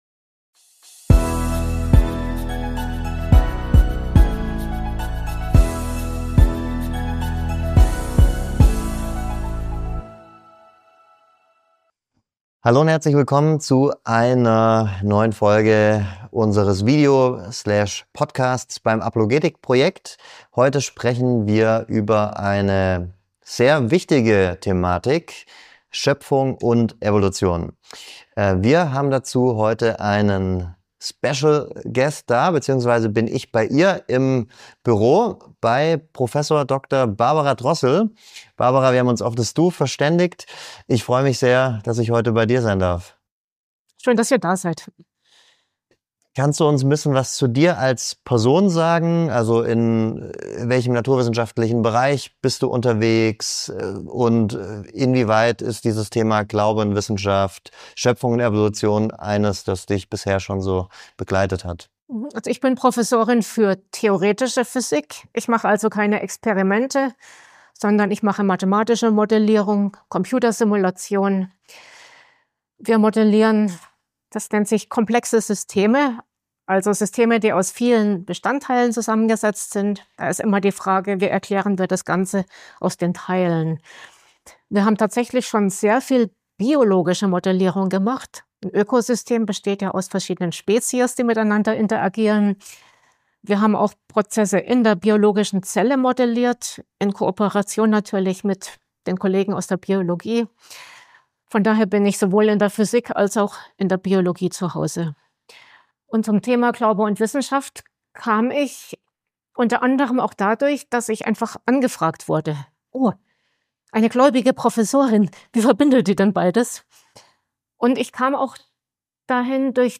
In diesem Gespräch erläutert sie einige ihrer zentralen Argumente, die für eine solche Vereinb...